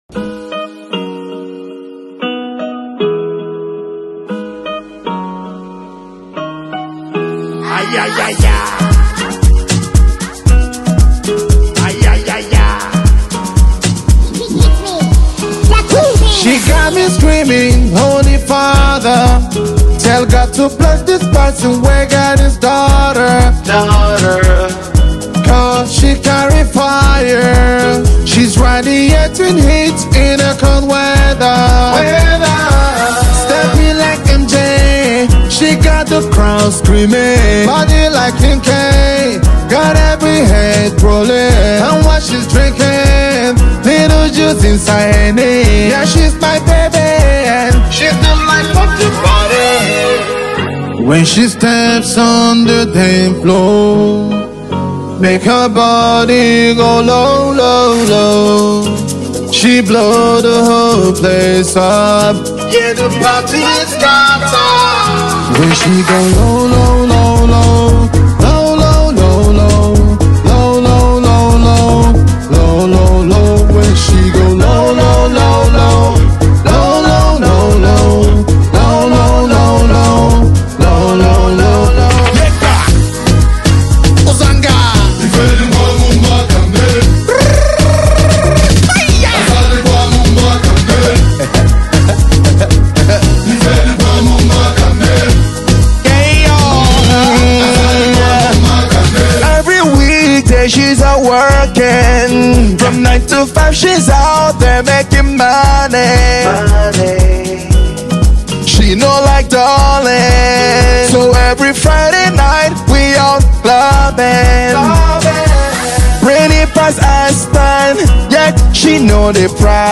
This infectious and groovy track
soulful vocals
the acclaimed Afrobeat artist